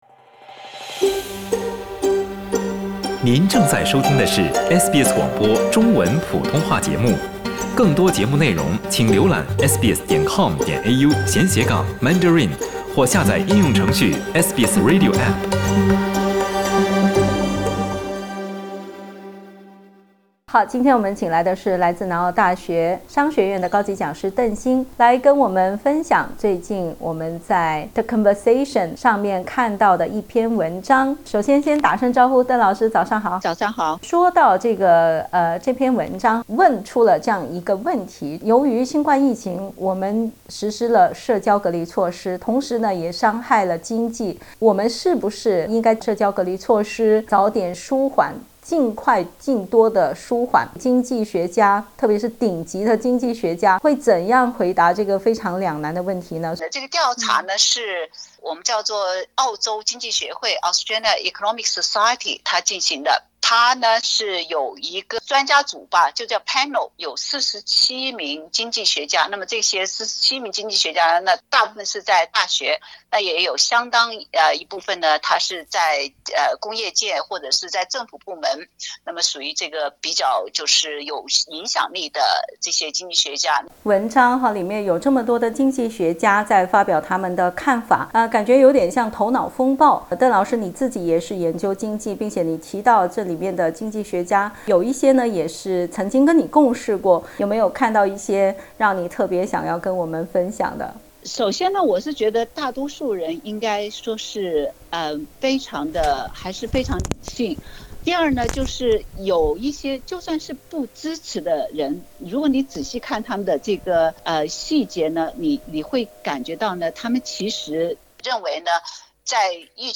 最近，47位澳大利亚顶级经济学家参与了澳大利亚经济学会的一项调查，表达他们对公共健康和经济健康之间如何进行权衡的看法。 点击图片收听详细报道。